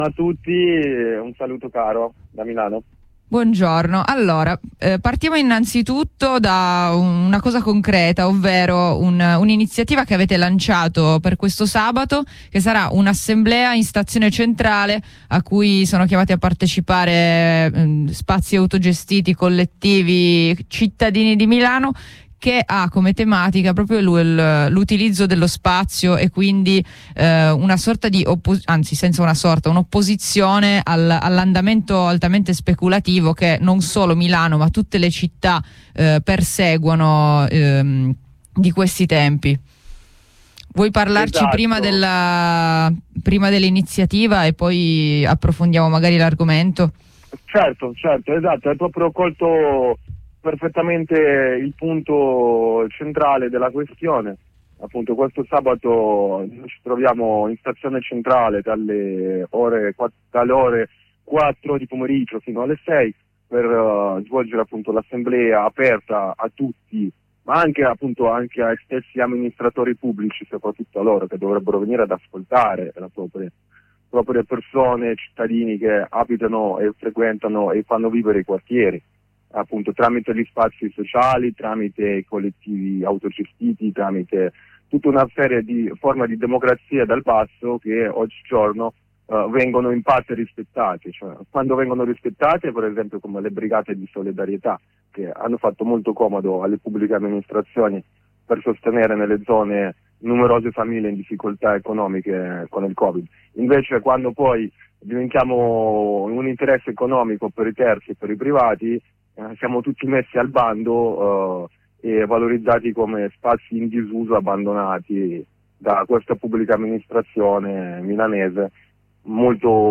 L’assemblea fa parte di un percorso di inizative che pongono al centro la costruzione della città e degli spazi in modo orizzontale, autogestito e dal basso. Ne abbiamo parlato con un compagno di Cascina Torchiera.